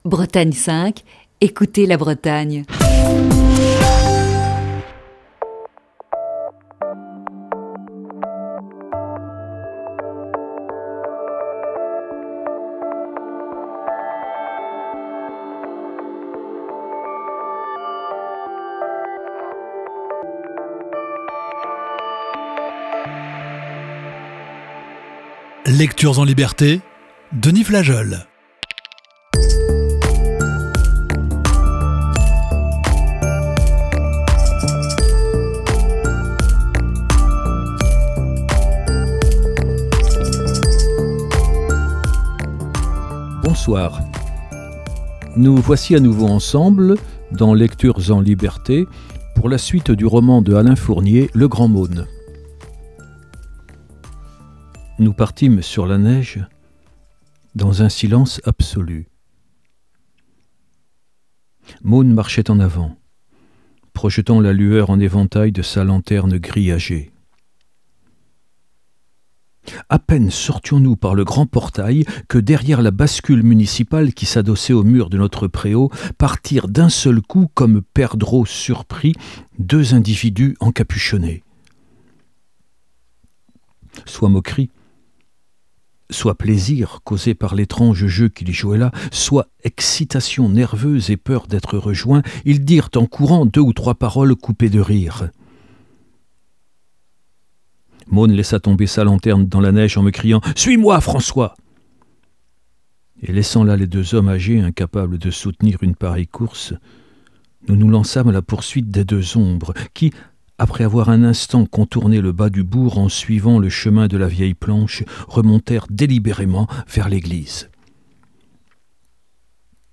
poursuit la lecture d'un classique de la littérature